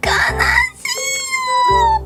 Worms speechbanks
Byebye.wav